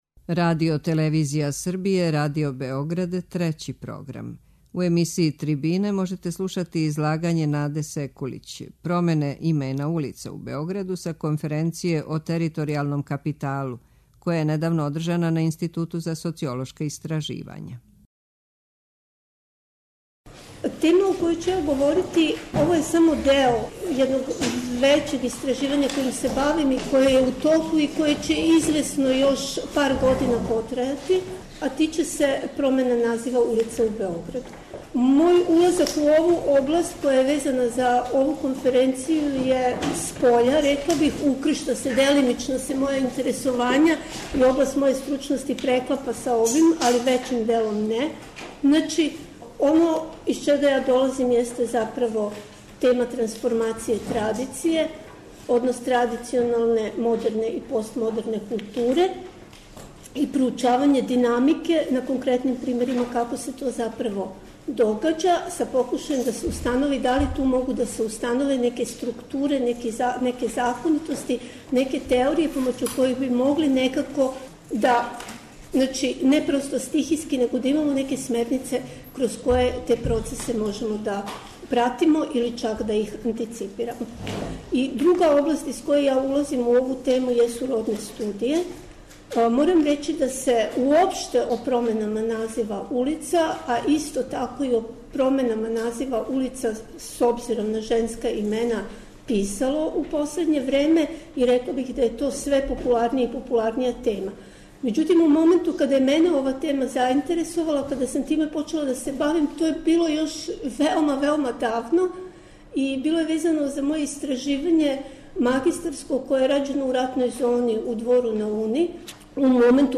преузми : 8.12 MB Трибине и Научни скупови Autor: Редакција Преносимо излагања са научних конференција и трибина.